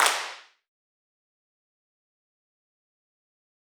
KanyeClap.wav